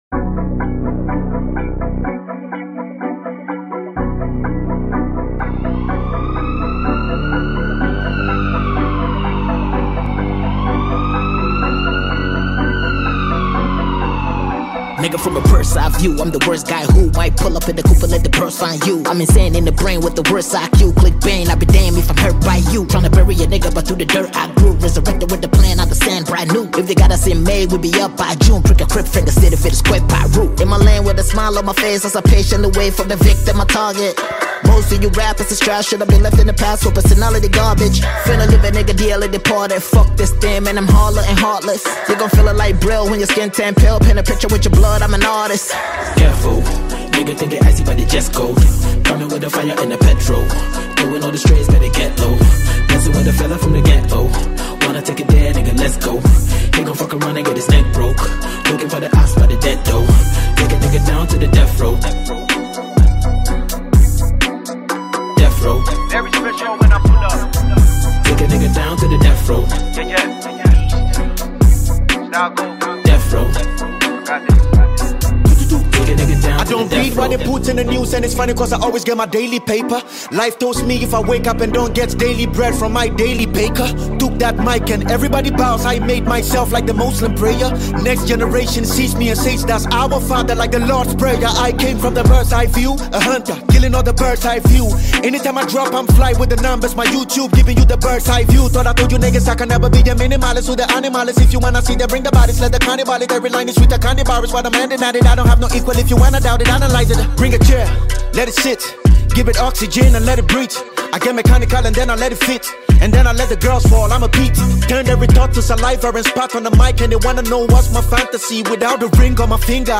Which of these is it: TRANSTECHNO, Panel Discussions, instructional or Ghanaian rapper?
Ghanaian rapper